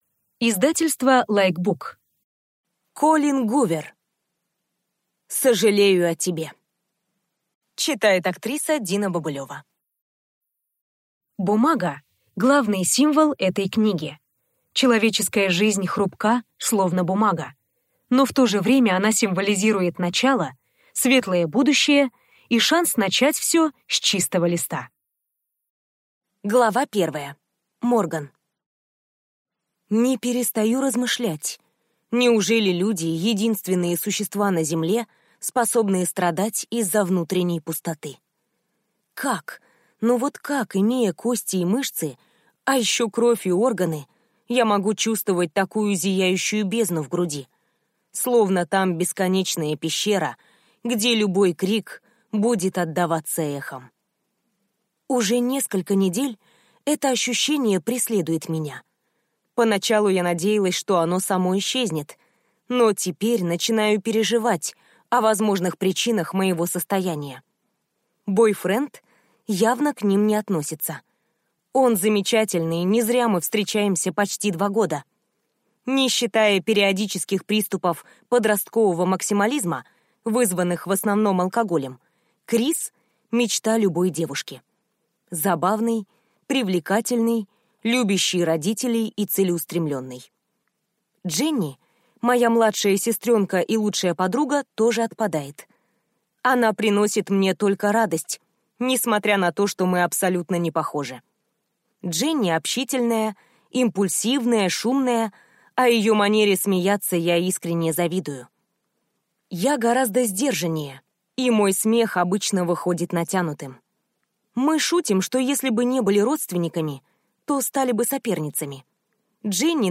Аудиокнига Сожалею о тебе | Библиотека аудиокниг